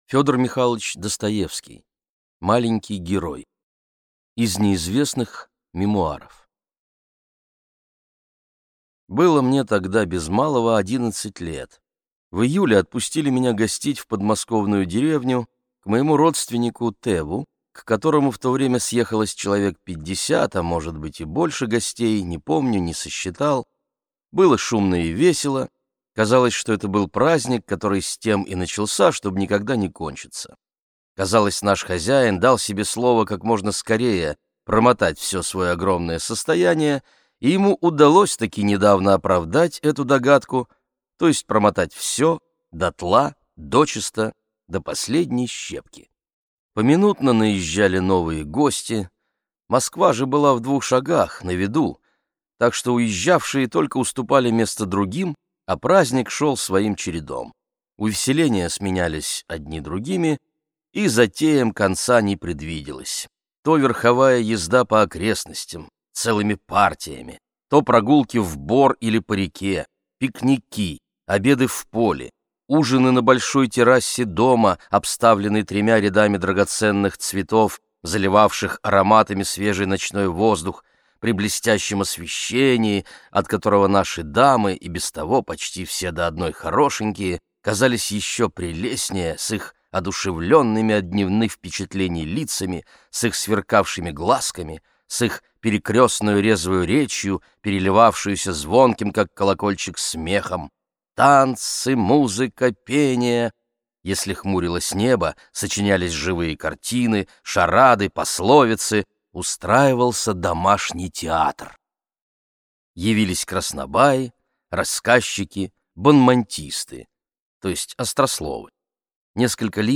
Аудиокнига Маленький герой | Библиотека аудиокниг